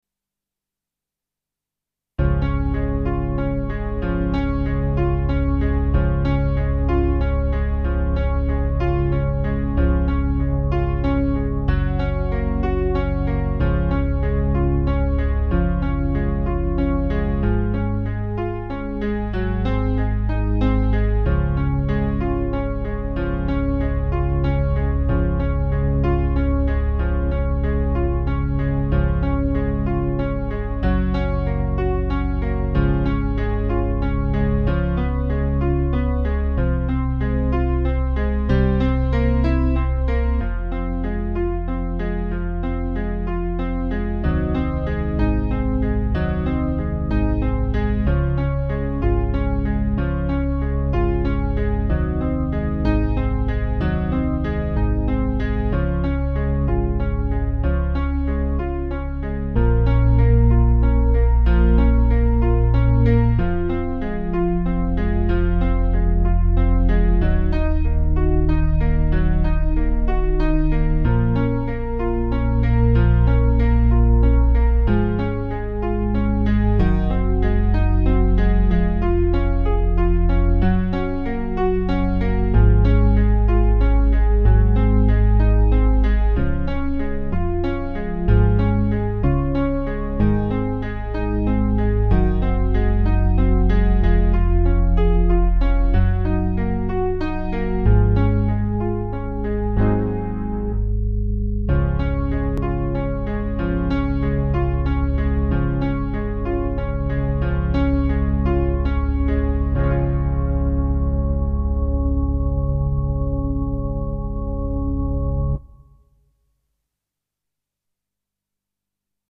O HOLY NIGHT Instrumental Version